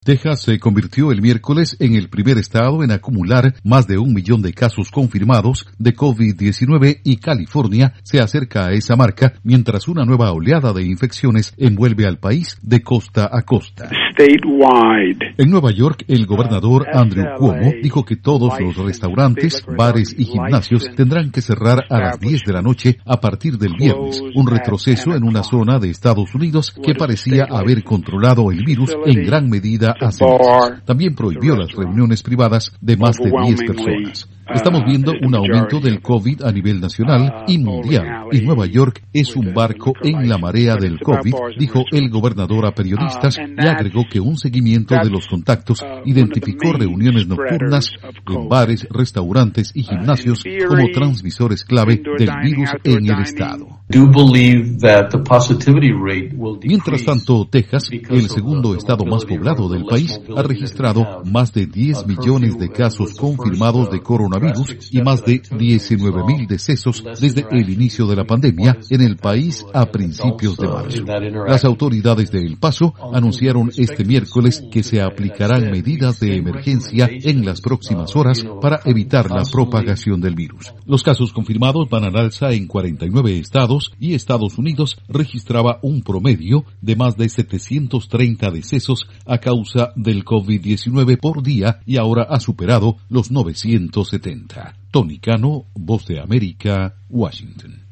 En Estados Unidos los casos confirmados de COVID-19 van al alza en 49 estados. Informa desde la Voz de América en Washington